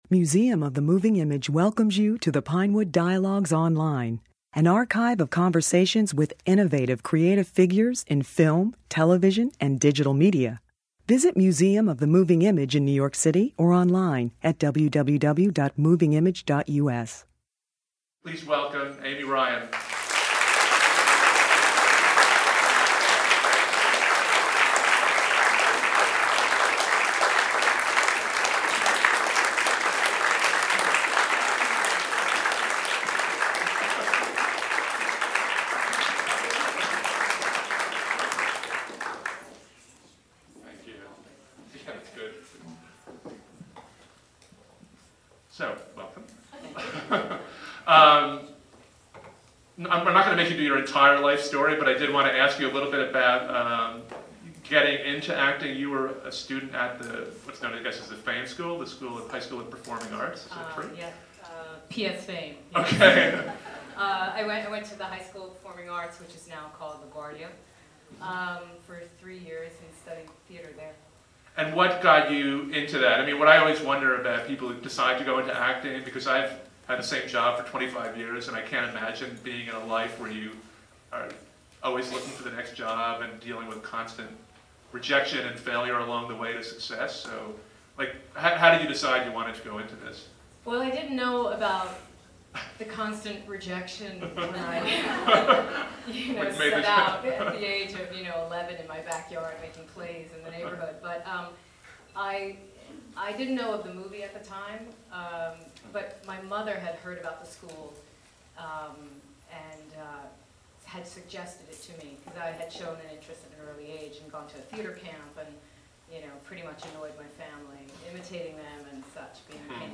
In a special Museum of the Moving Image event, Ryan spoke about her career before a screening of her Jac